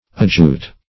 Adjute \Ad*jute"\